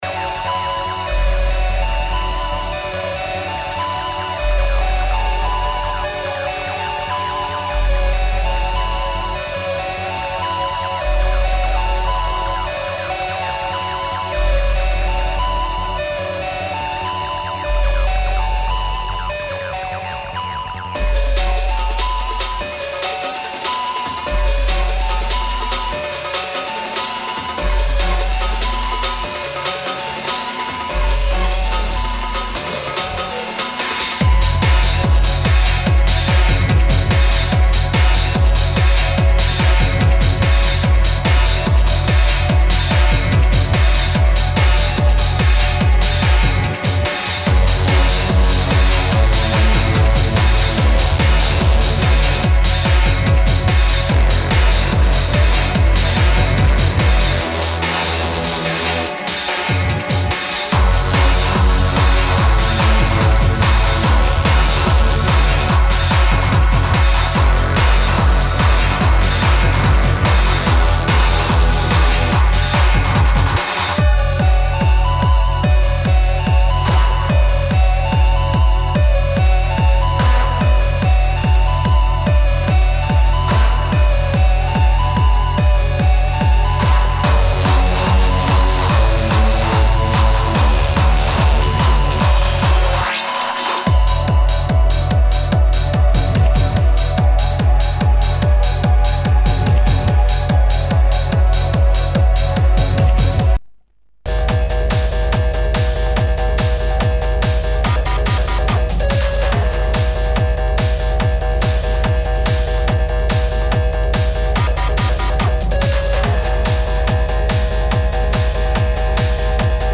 Techno-Electro